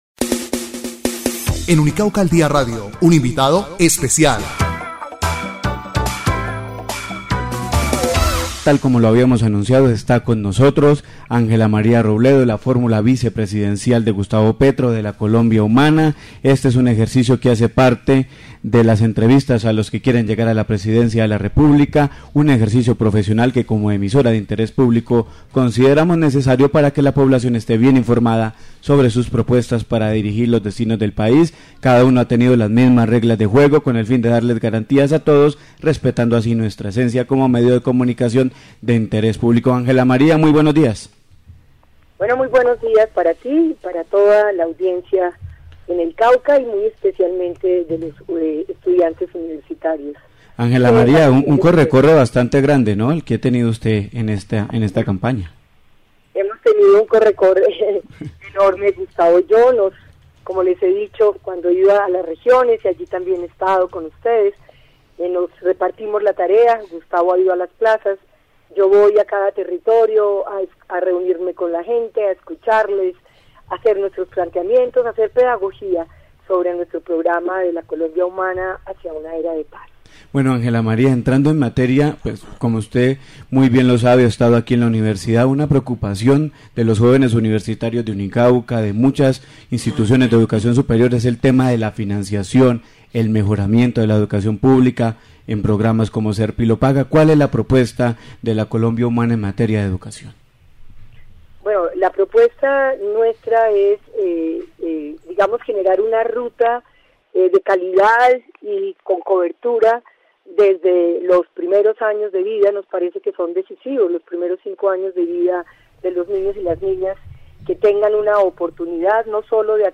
Ángela María Robledo en "Unicauca al Día Radio" 1 comentarios Continúan las entrevistas con los aspirantes a la Presidencia de la República en Unicauca al Día Radio 104.1 FM. Entrevista con Ángela María Robledo